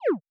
SFX_Dialog_Close_02.wav